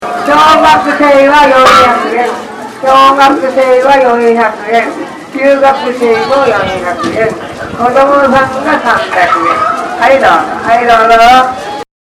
祭りの中MacBookを持って録音をしてきました
予想以上に内臓マイクの音質が良かったのですが、